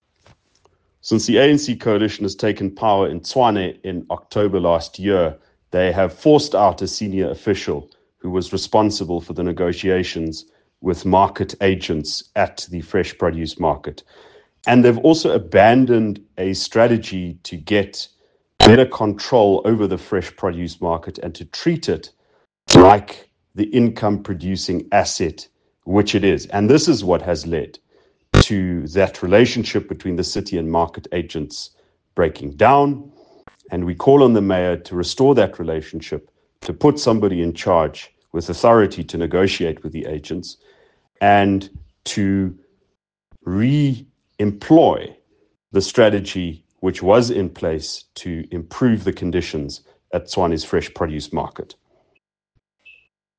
Note to Editors: Please find an English and Afrikaans soundbite by Ald Cilliers Brink